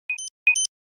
Battery is empty.wav